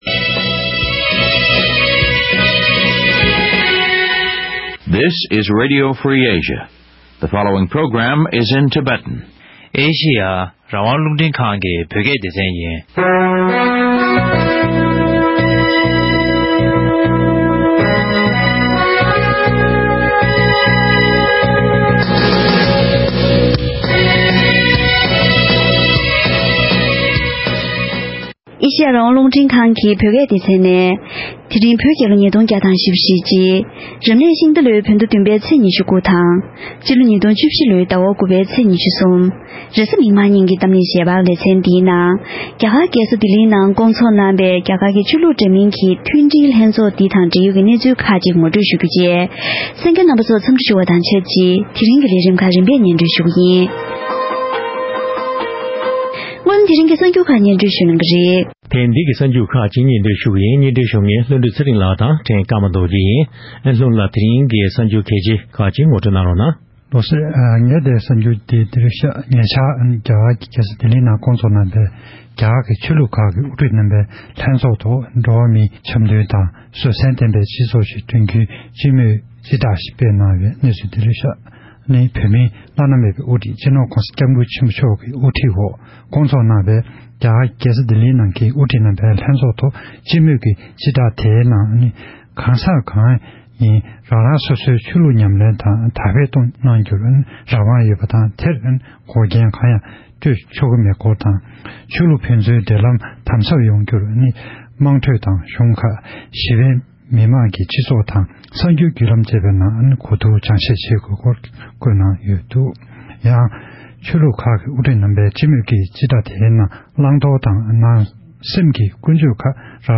༄༅༎དེ་རིང་གི་གཏམ་གླེང་ཞལ་པར་ལེ་ཚན་ནང་ཚེས་༢༠དང་༢༡ཉིན་གཉིས་རིང་རྒྱ་གར་གྱི་རྒྱལ་ས་ལྡི་ལིའི་ནང་སྐོང་ཚོགས་གནང་པའི་རྒྱ་གར་ནང་གི་ཆོས་ལུགས་འདྲ་མིན་གྱི་མཐུན་སྒྲིལ་ལྷན་ཚོགས་འདིའི་ཞིབ་ཕྲའི་གནས་ཚུལ་ཁག་གི་ཐོག་འབྲེལ་ཡོད་མི་སྣ་དང་ལྷན་བཀའ་མོལ་ཞུས་པ་ཞིག་གསན་རོགས་གནང་།།